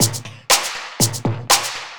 TSNRG2 Breakbeat 017.wav